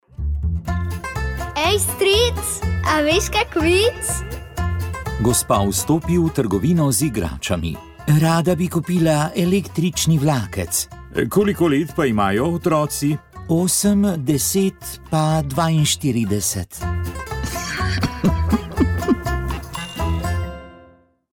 kontaktni oddaji